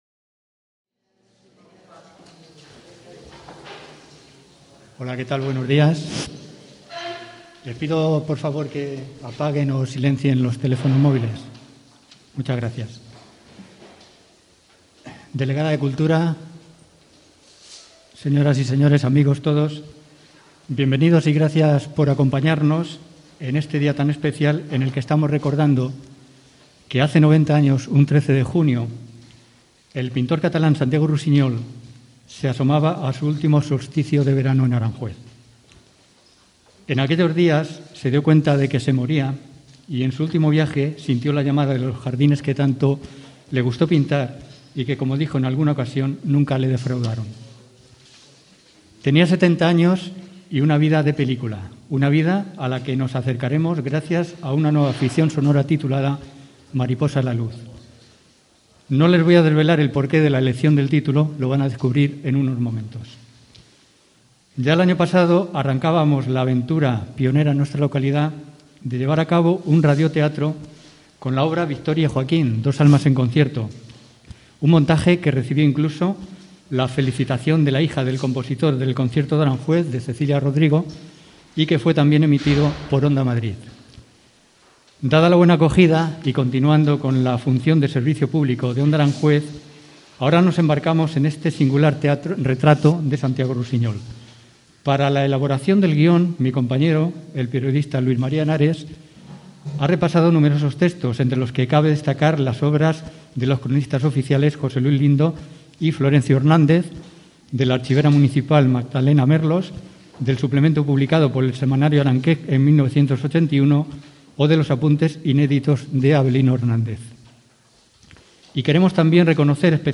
Onda Aranjuez ficciona una entrevista a Santiago Rusiñol amb un radioteatre per recordar el 90è aniversari de la seva mort